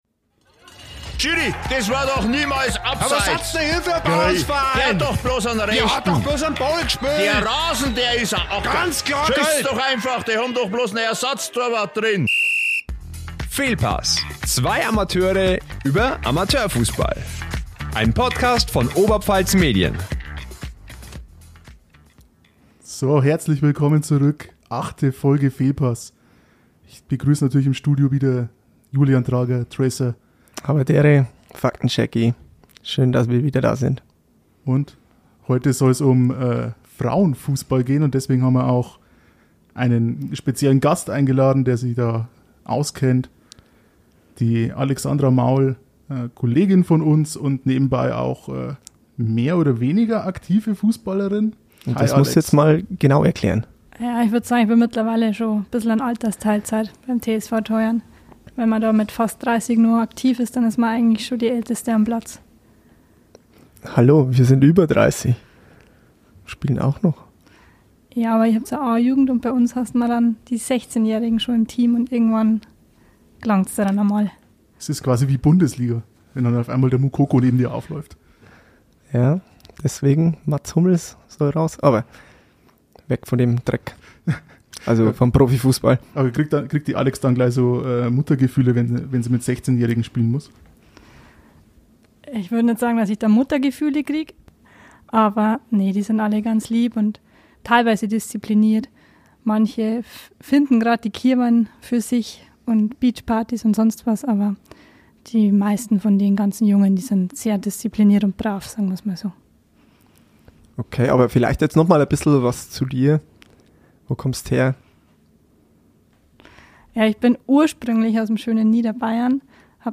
Und ein paar Sprachnachrichten haben sie auch erhalten.